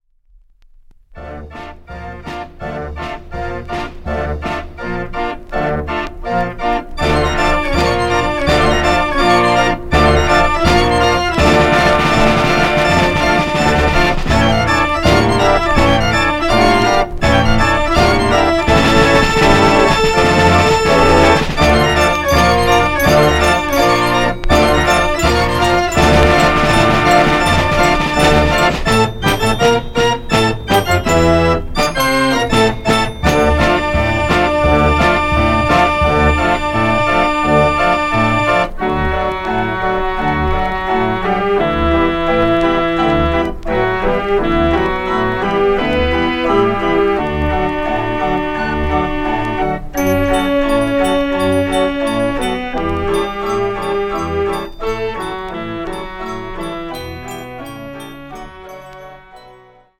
en een kenmerkende theaterstijl in de arrangementen.